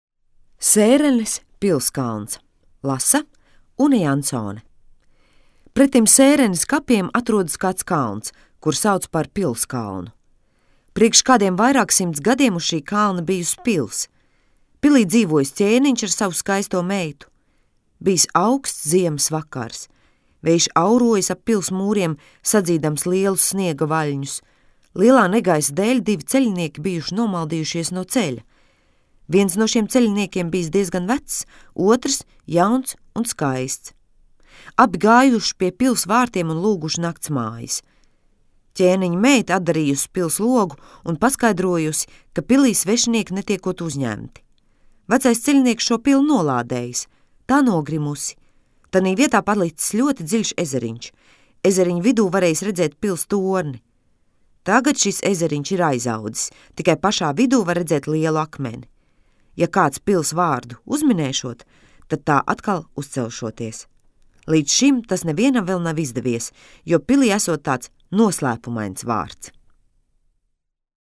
Teikas